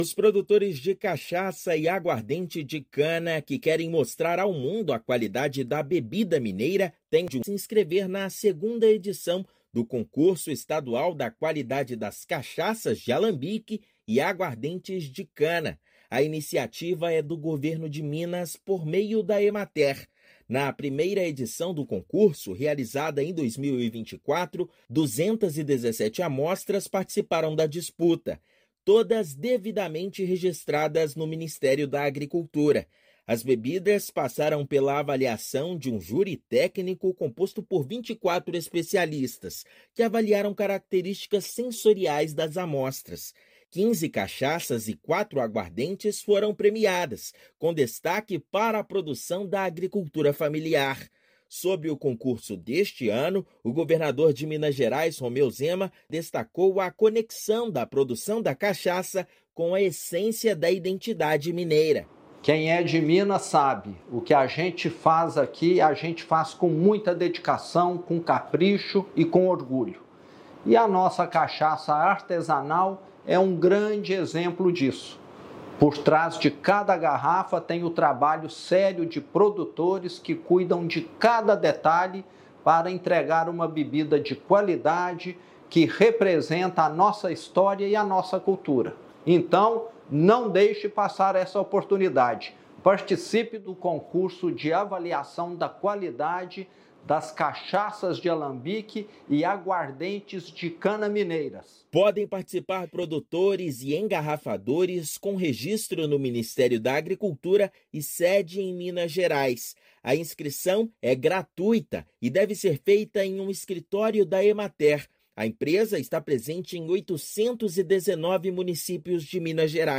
Produtores de cachaça e aguardente de cana ganham mais tempo para participar da premiação organizada pela Emater-MG. Ouça matéria de rádio.